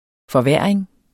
Udtale [ fʌˈvæːɐ̯eŋ ]